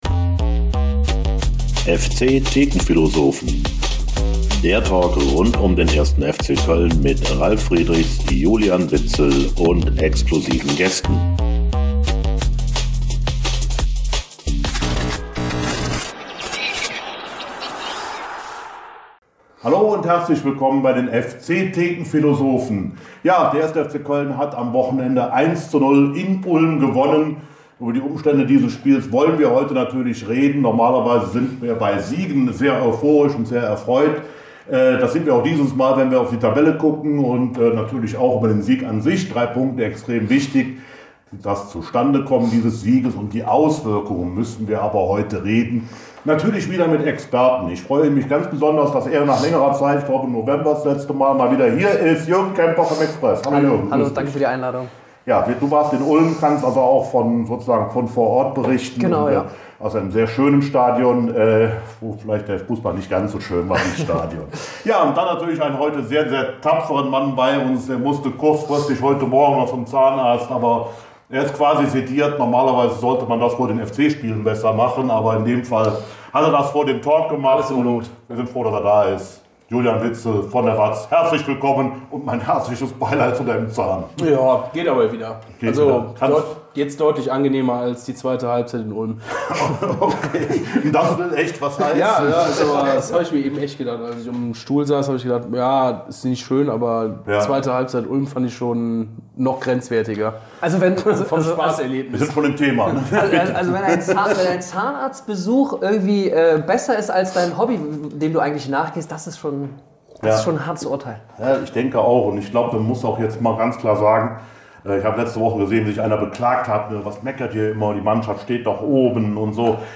Gegen Ende des Videos werden zudem viele Zuschauerfragen der letzten Tage verlesen und beantwortet.